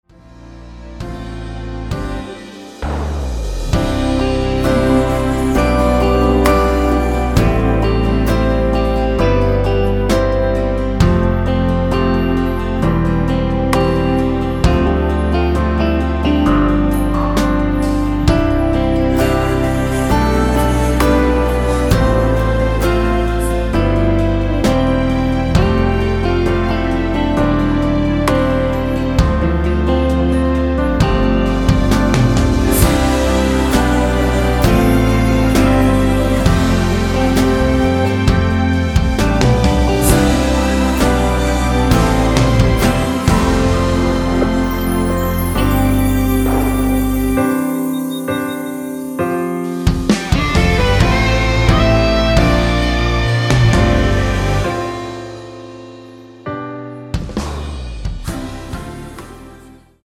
원키에서(-1)내린 코러스 포함된 MR입니다.(미리듣기 확인)
◈ 곡명 옆 (-1)은 반음 내림, (+1)은 반음 올림 입니다.
앞부분30초, 뒷부분30초씩 편집해서 올려 드리고 있습니다.